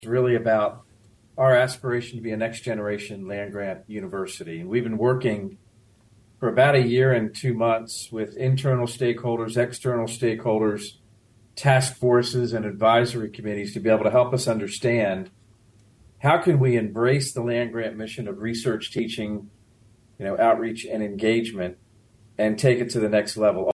Linton said that this process was about having the university embrace the makings of a land grant university.